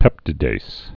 (pĕptĭ-dās, -dāz)